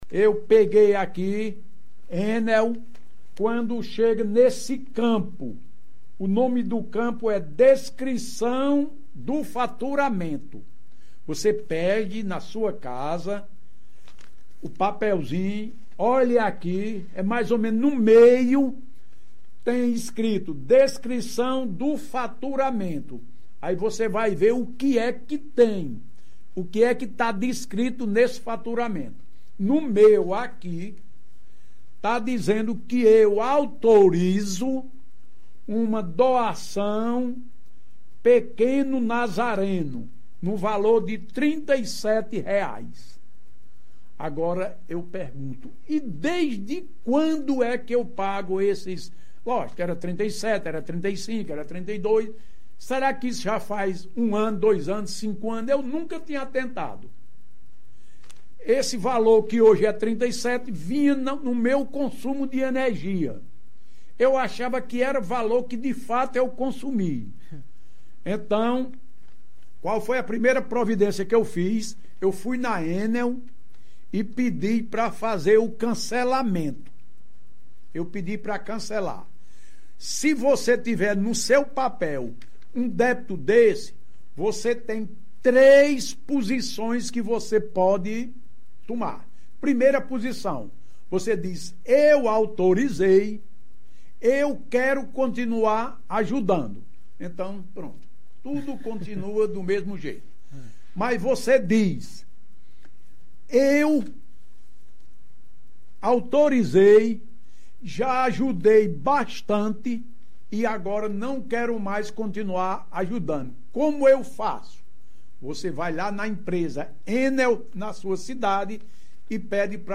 Em participação no Jornal Giro 360, da Rádio Cultura – FM 96.3, na noite desta sexta-feira, 02 de maio, o ex-vereador e ex-bancário Luiz Correia, chamou atenção para outras formas de descontos indevidos, agora na conta de energia.
Confira trecho da entrevista: